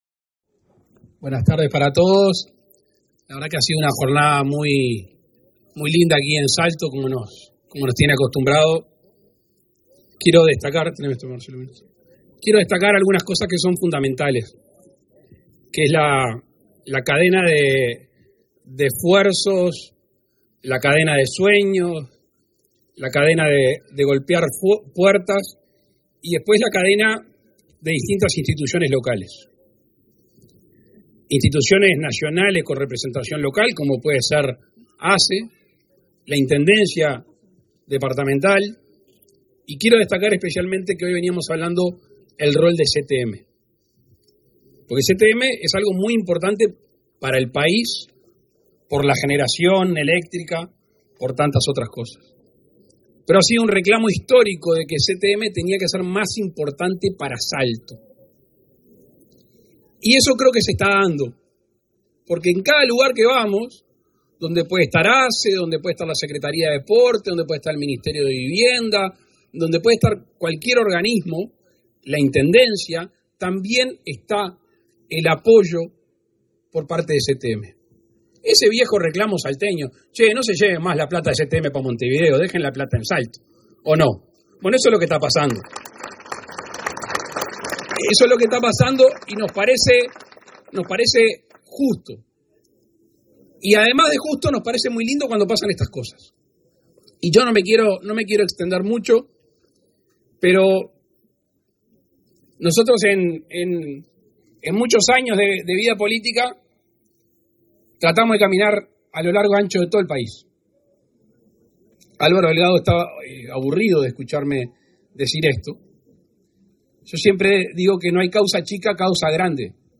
Palabras del presidente de la República, Luis Lacalle Pou
El presidente de la República participó, este 20 de abril, en la inauguración de la policlínica en Colonia Harriague, ubicada cerca de la ruta 3, en